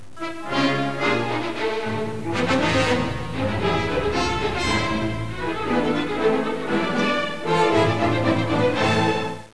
なお、音質は著作権への配慮で、ノイズを付加したものです。
V．ロンド・ブルレスケ　極めて反抗的にと記されたロンド。
ロンド主題(207K)はトランペットと弦の短い序奏の後に第１ヴァイオリンで激しく現れ、やがて少しおさまって副主題(176K)が提示されます。